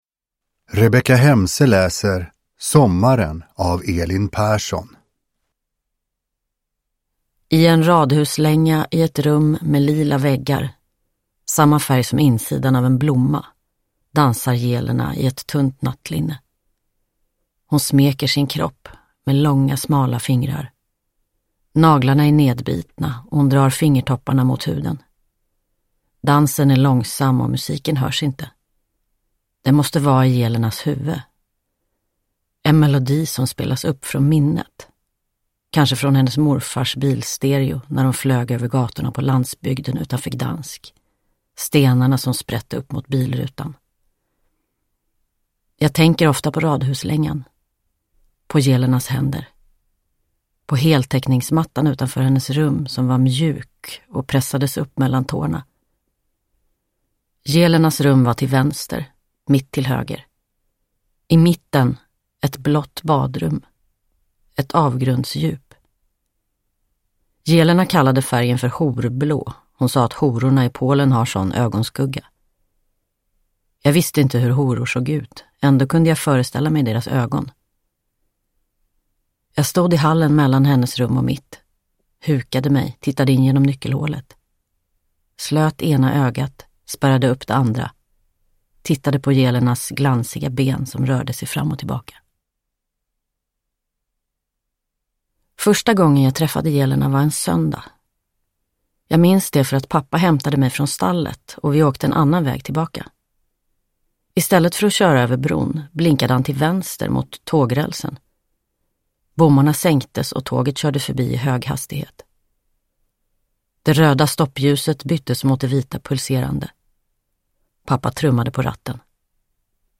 Sommaren – Ljudbok – Laddas ner
Uppläsare: Rebecka Hemse